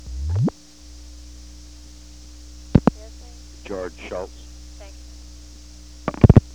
• President Richard M. Nixon
• White House operator
Location: White House Telephone
The President talked with the White House operator.